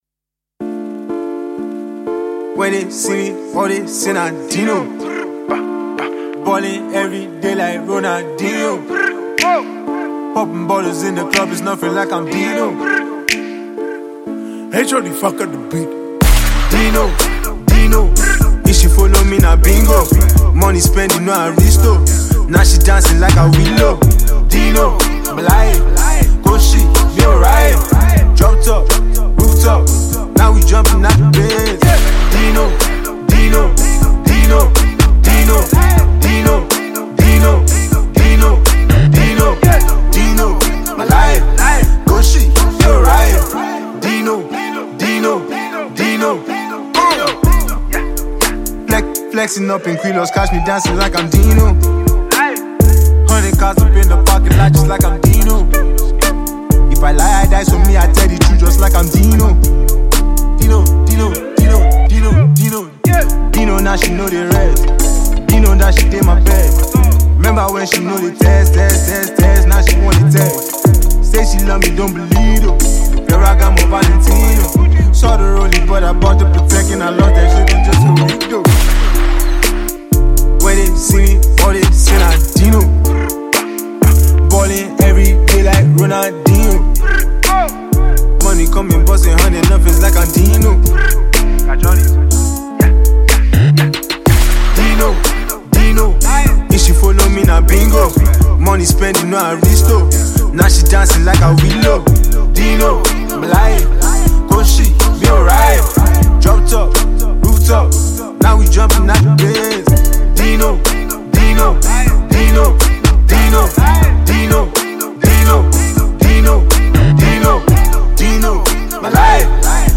AfroTrap